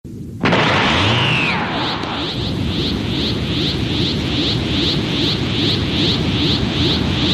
Dbz Powerup
DBZ-Powerup.mp3